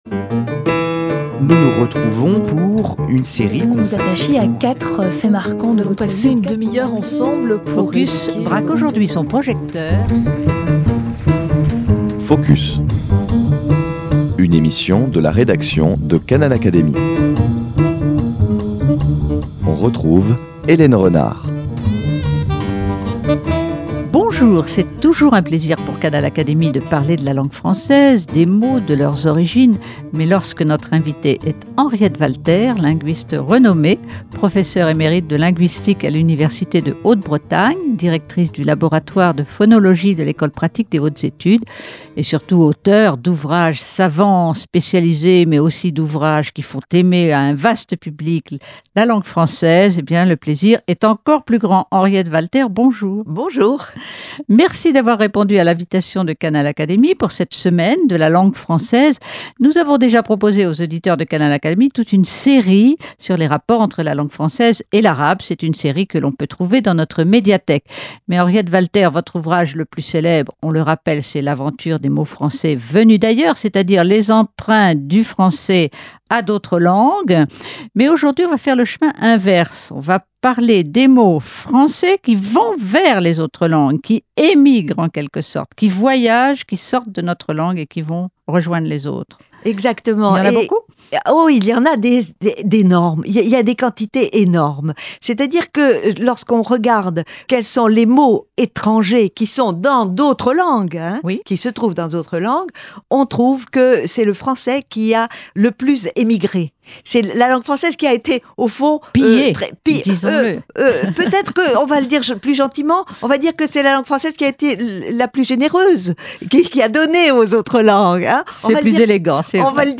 C’est toujours un plaisir pour Canal Académie de parler de la langue française, des mots et de leurs origines, surtout lorsque notre invitée est Henriette Walter, linguiste renommée, professeur émérite de linguistique à l’Université de Haute Bretagne, directrice du laboratoire de phonologie à l’Ecole Pratique des Hautes Etudes et surtout auteur d’ouvrages savants, spécialisés, mais aussi d’ouvrages qui ont fait aimer l’histoire des mots et de la langue française à un vaste public.